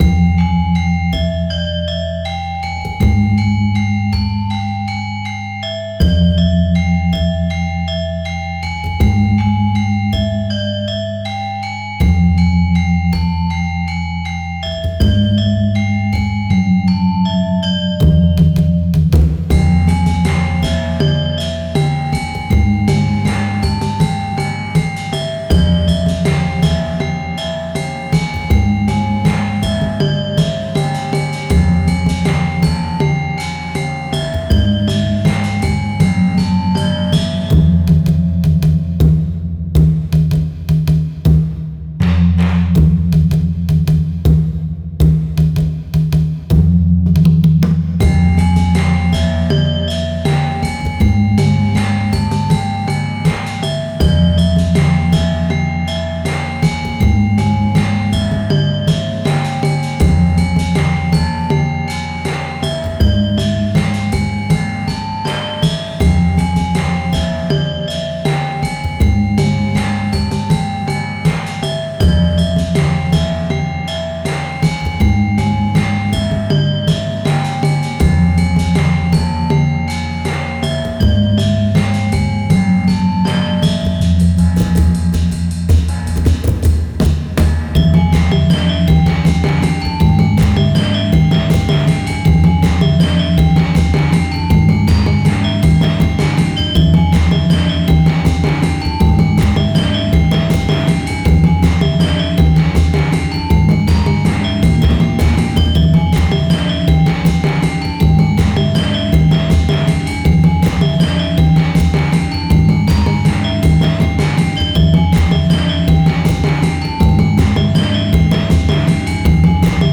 主な使用楽器は、ガムラン、金属打楽器、低音パーカッションなど。
• ジャンル：ワールドミュージック／民族系BGM／ファンタジー／アンビエント
• 雰囲気：神秘的 / 不穏 / 儀式的 / 幻想的